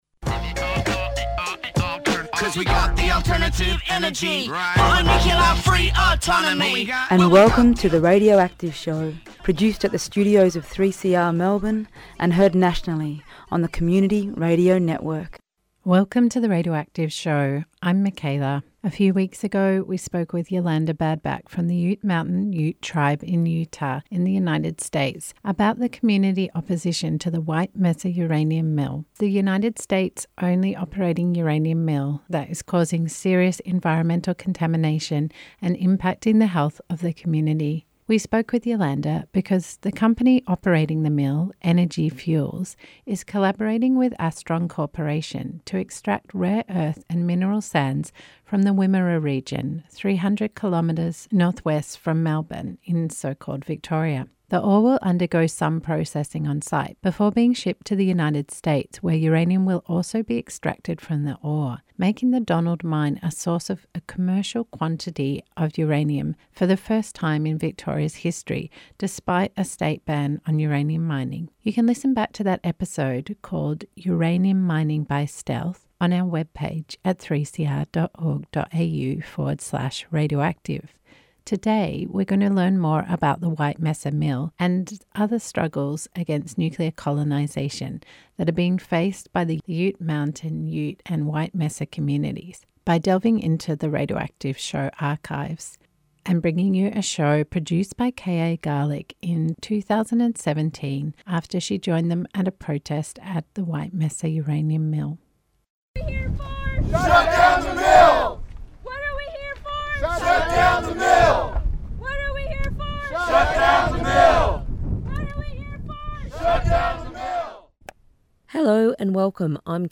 This show from the archives 'Uranium Mill Protest on Native American Land' features recordings from the Ute Mountain Ute Native American protest against the White Mesa Uranium Mill on their land from April 2017.